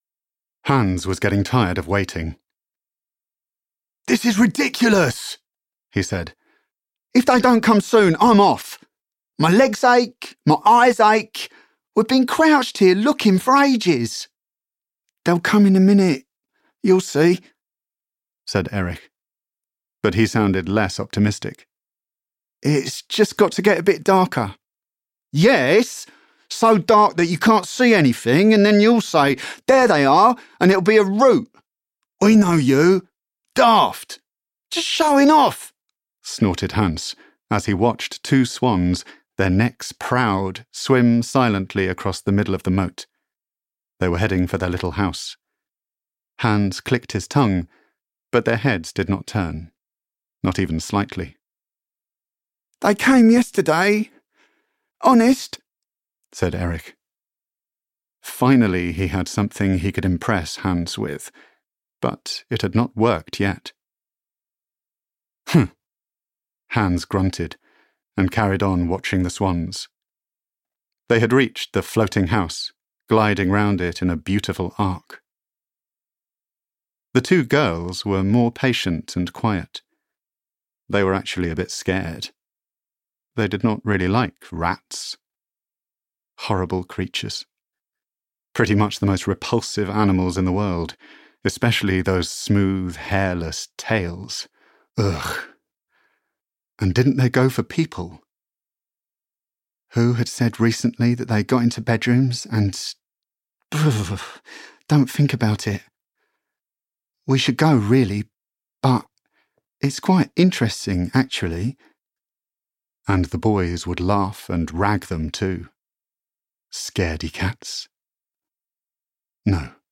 At the Edge of the Night audiokniha
Ukázka z knihy